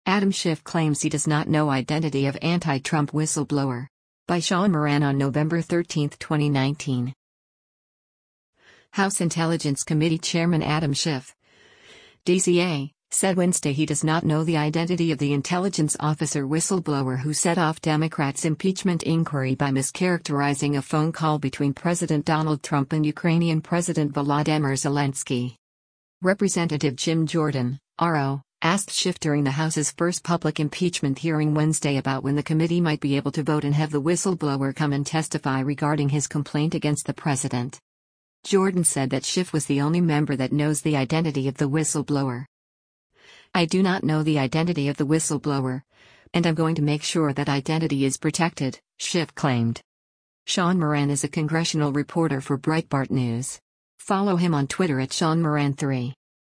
Rep. Jim Jordan (R-OH) asked Schiff during the House’s first public impeachment hearing Wednesday about when the committee might be able to vote and have the whistleblower come and testify regarding his complaint against the president.